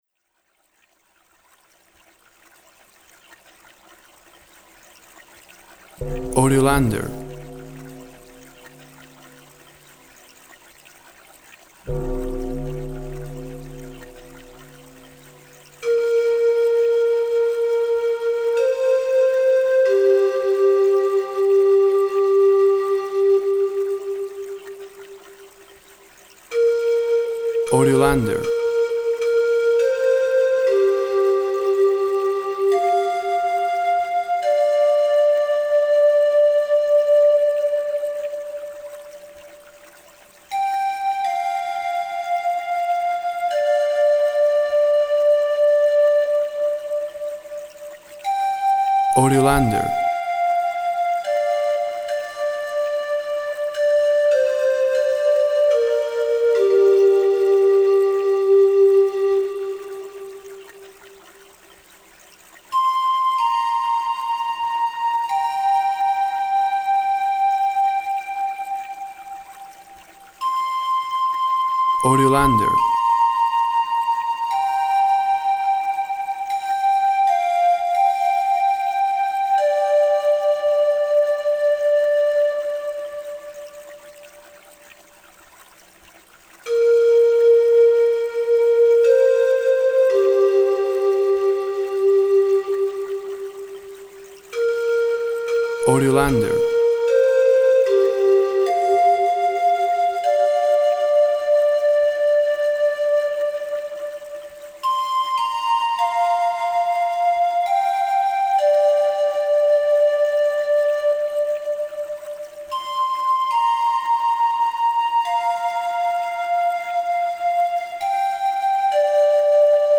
Water babbling in a stream inspires a wooden flute song.
WAV Sample Rate 24-Bit Stereo, 44.1 kHz
Tempo (BPM) 60